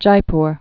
(jīpr)